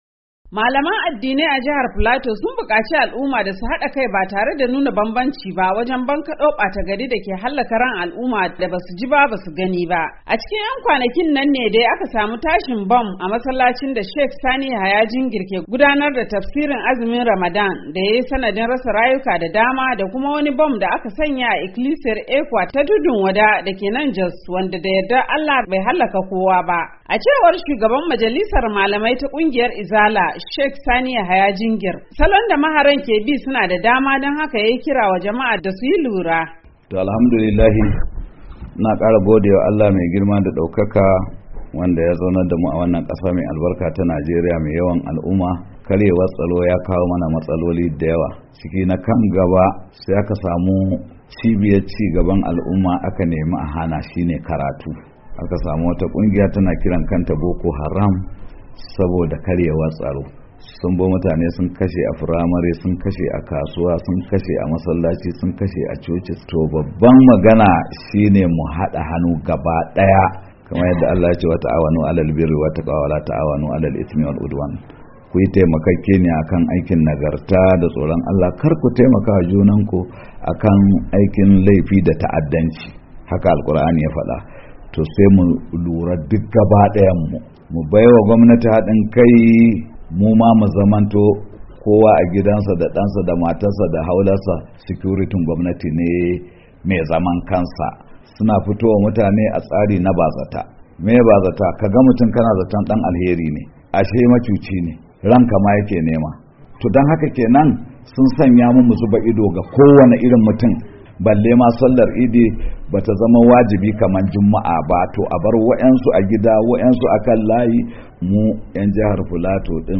Rahoton Kiran Shugabannin Addinai - 3'45"